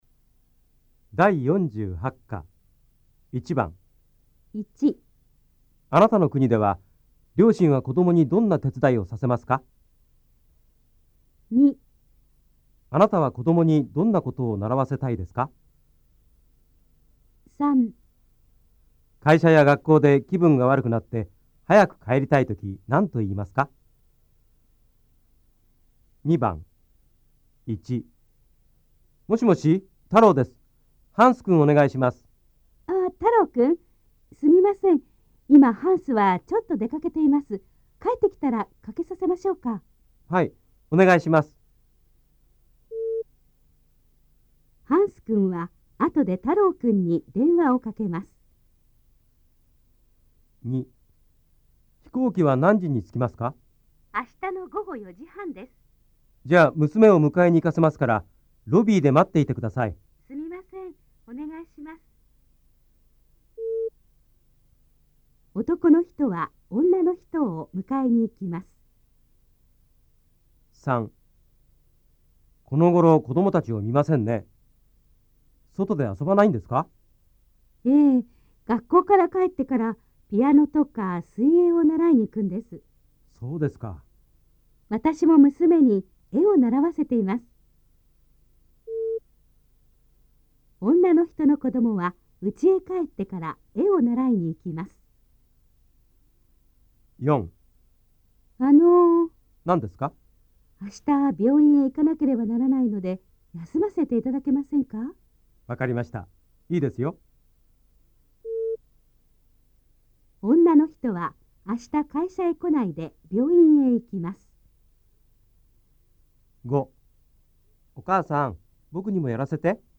大家的日语-第48课听力练习